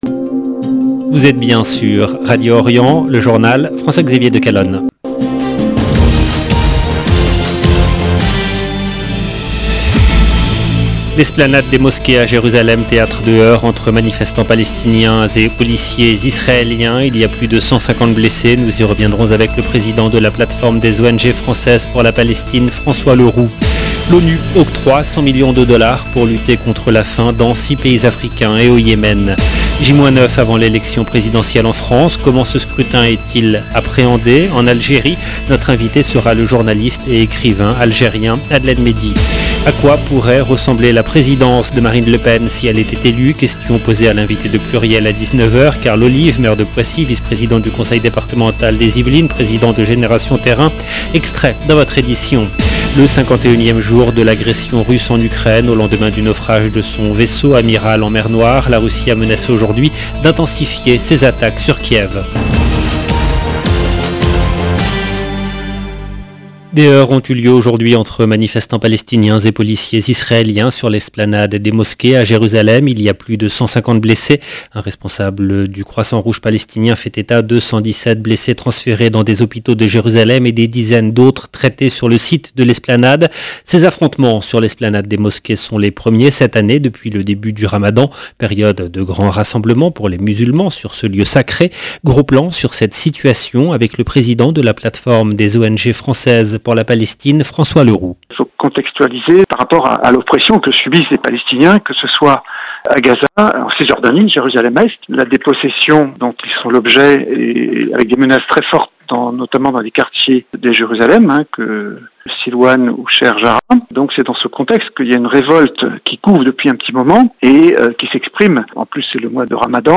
LE JOURNAL DU SOIR EN LANGUE FRANCAISE DU 15/4/2022